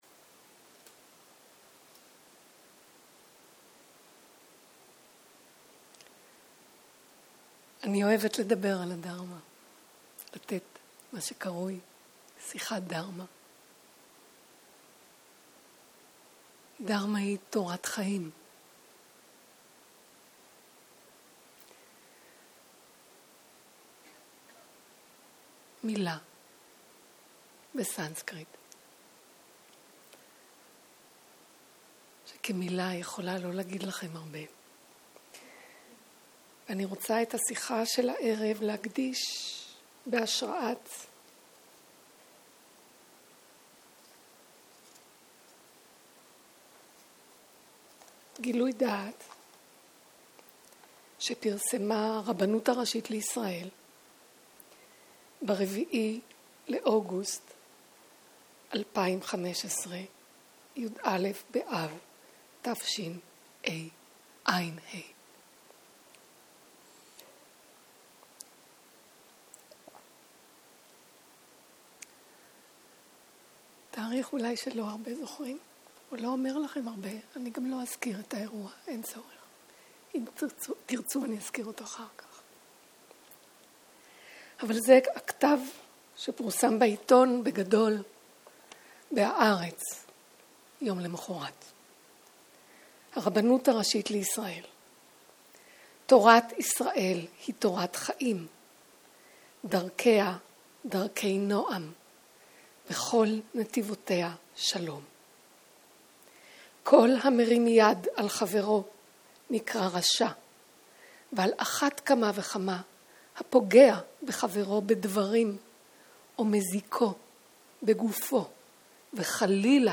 ערב - שיחת דהרמה - מהו שלום בתרגול - הקלטה 4